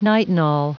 Prononciation du mot nitinol en anglais (fichier audio)
Prononciation du mot : nitinol